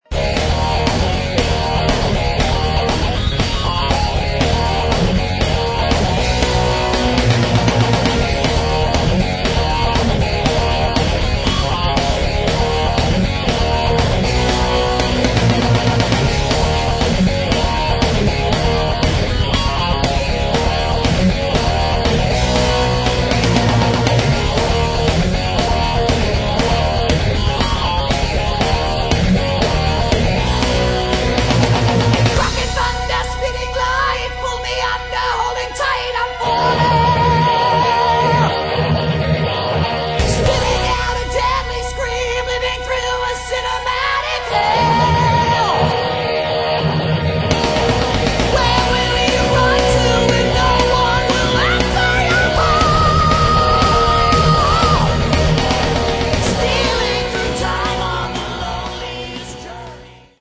Metal
это однозначно направленный мелодичный тяжёлый металл